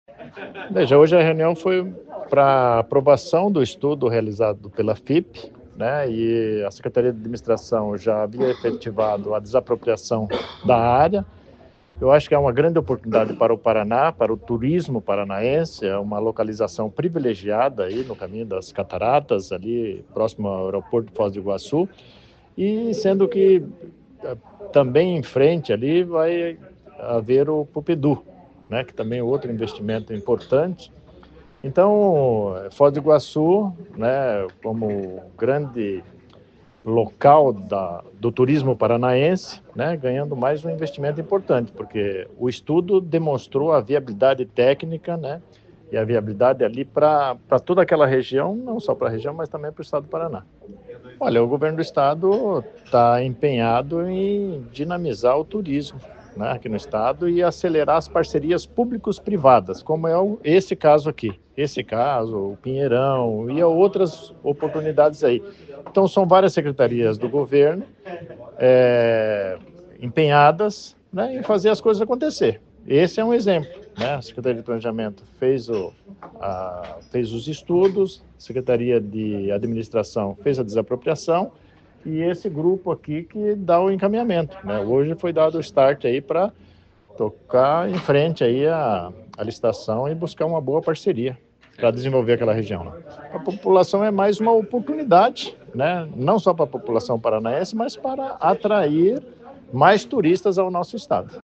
Sonora do secretário de Administração e Previdência, Luizão Goulart, sobre a aprovação do projeto de concessão de uso do Centro de Convenções de Foz do Iguaçu